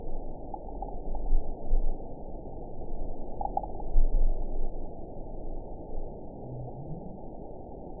event 912532 date 03/28/22 time 21:16:19 GMT (3 years, 9 months ago) score 9.36 location TSS-AB03 detected by nrw target species NRW annotations +NRW Spectrogram: Frequency (kHz) vs. Time (s) audio not available .wav